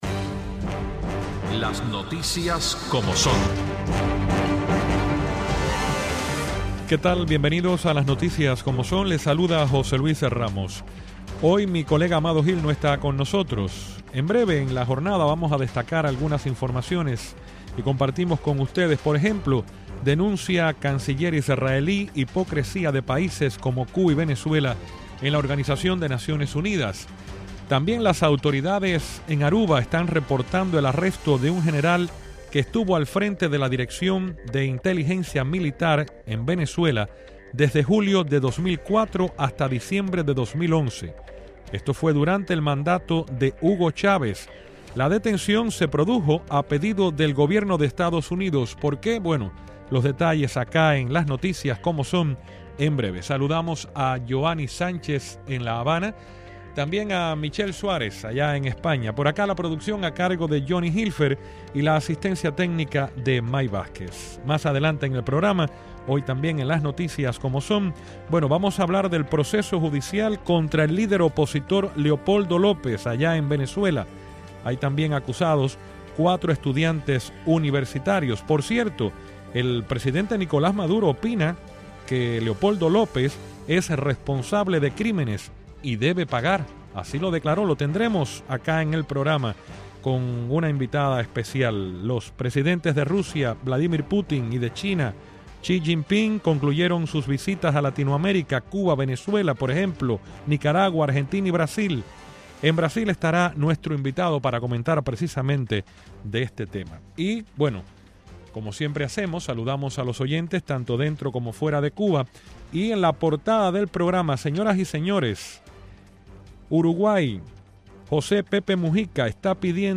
Los periodistas, Yoani Sánchez, desde La Habana